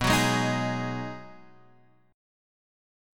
B6 Chord